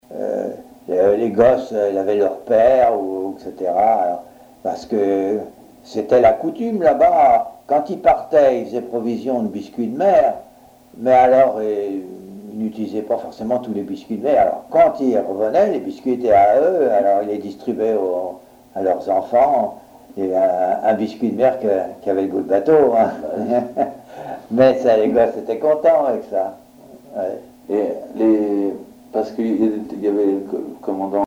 Témoignages sur la construction navale à Fécamp
Catégorie Témoignage